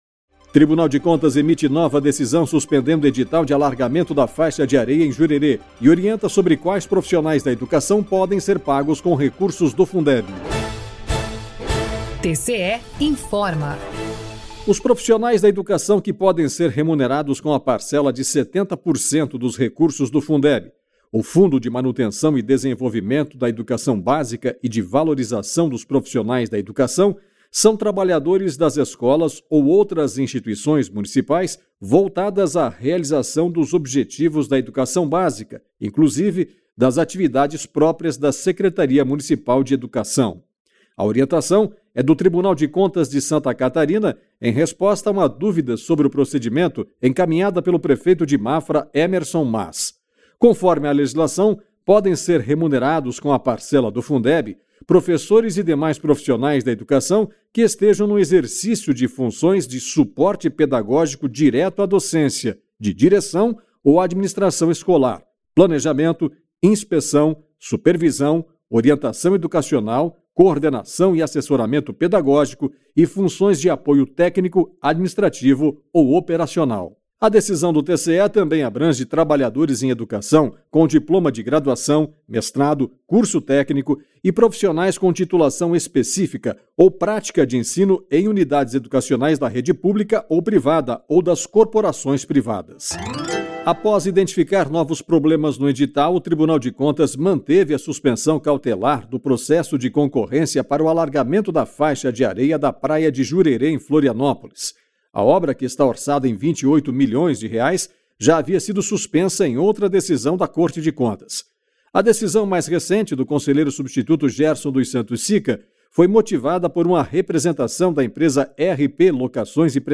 VINHETA TCE INFORMA